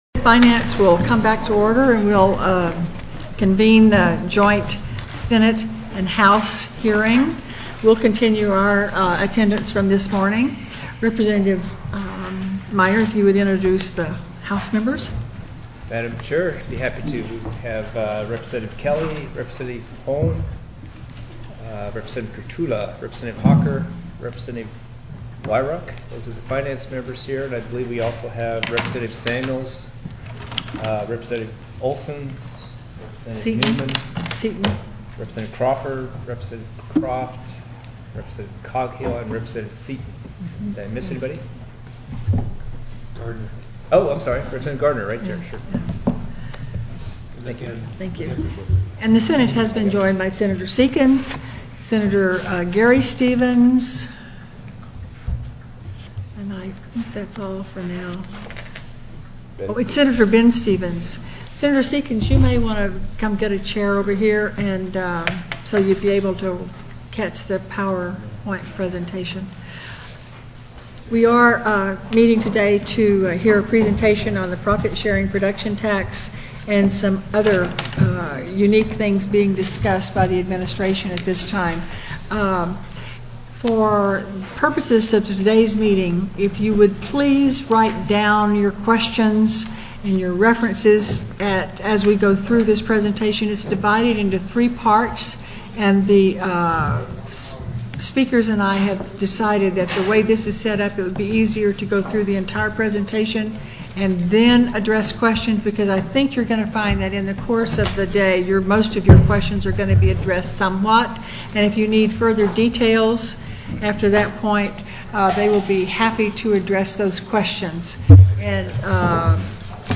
Joint w/(H) Finance TELECONFERENCED Overview: Profit Sharing Production Tax Analysis; New Investments & International Competition